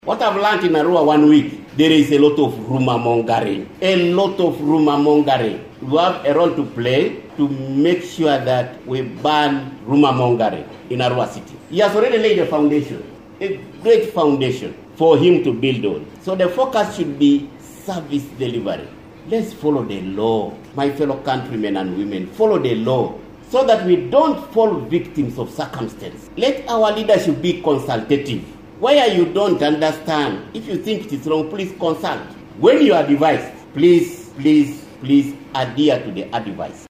During the handover ceremony, Yako Teddy, the Speaker for Arua City Council, commended the efforts of the outgoing city clerk, recognizing the solid foundations he laid during his tenure. She encouraged the incoming city clerk to take on the responsibilities with dedication and strive for excellence.
11_09 - SPEAKER ON CITYCLERK.mp3